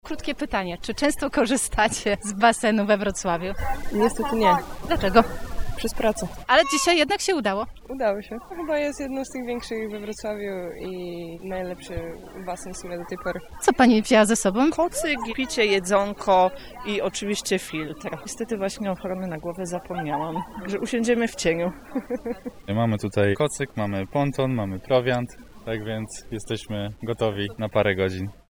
sonda_woda-basen-lato.mp3